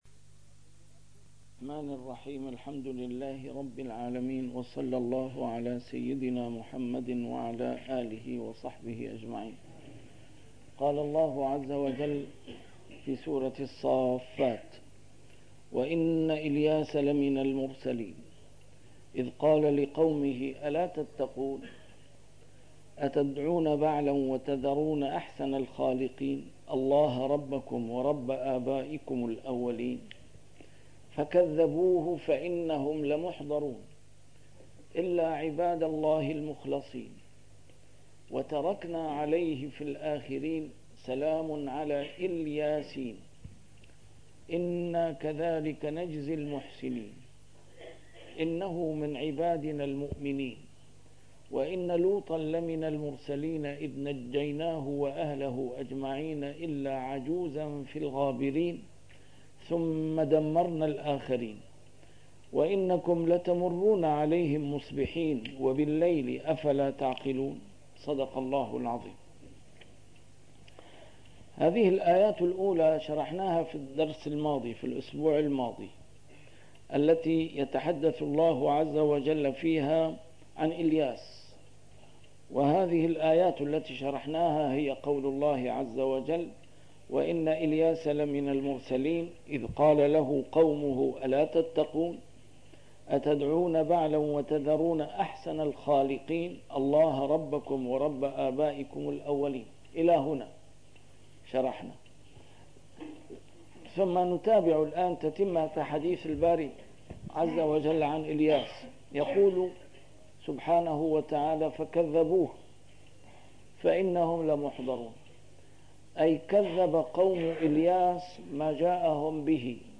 A MARTYR SCHOLAR: IMAM MUHAMMAD SAEED RAMADAN AL-BOUTI - الدروس العلمية - تفسير القرآن الكريم - تسجيل قديم - الدرس 457: الصافات 127-138